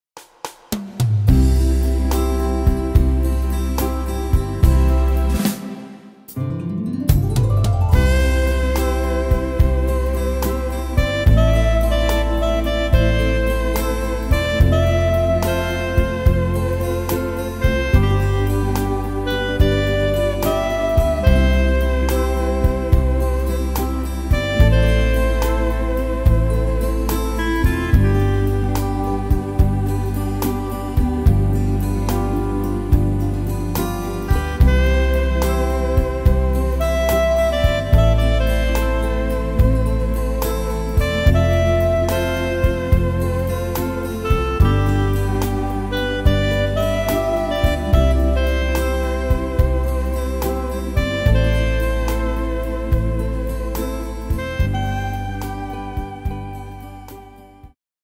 Tempo: 72 / Tonart: C-Dur